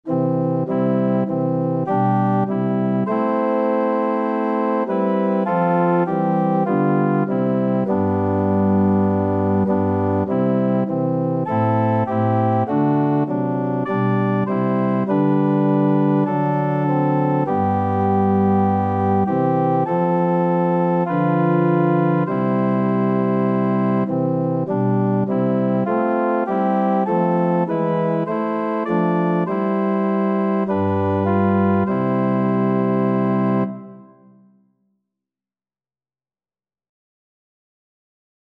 4/4 (View more 4/4 Music)
Organ  (View more Intermediate Organ Music)
Classical (View more Classical Organ Music)